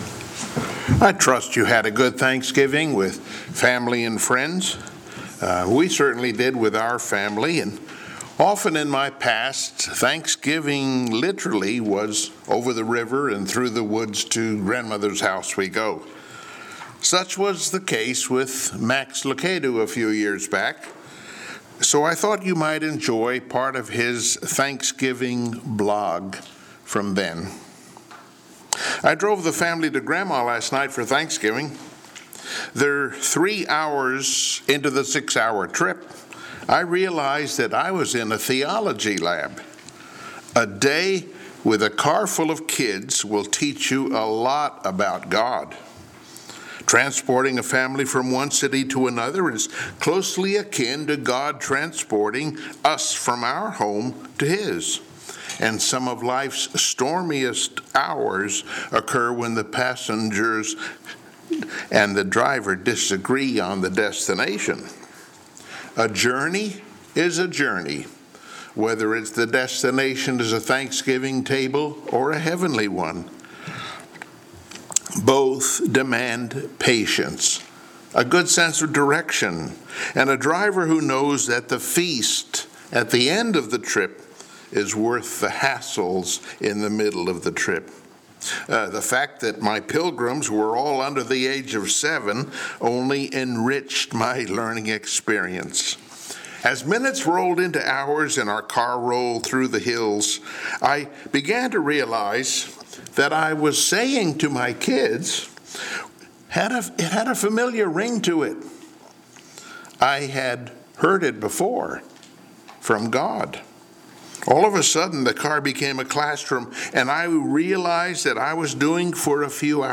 Passage: 1 Peter 1:8-9 Service Type: Sunday Morning Worship Psalm 39.5 You have made my days a mere handbreadth